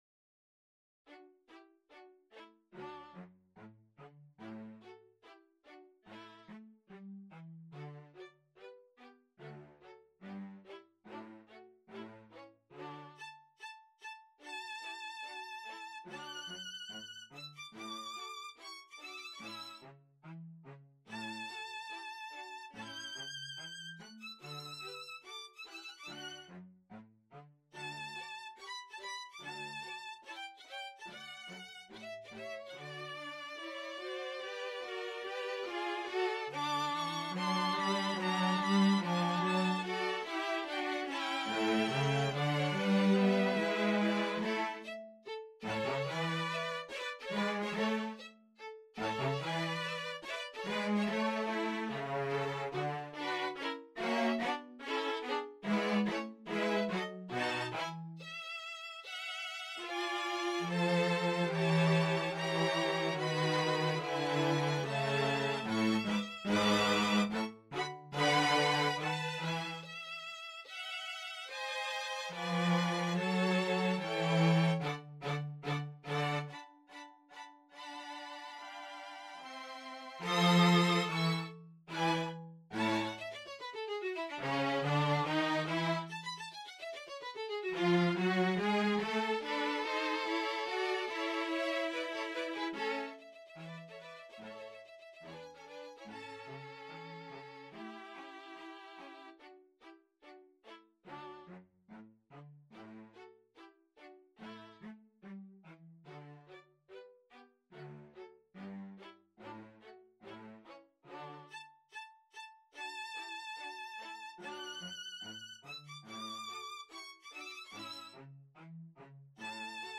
Instrument: String Quartet
Style: Classical
haydn_string_quartet_op67_5.mp3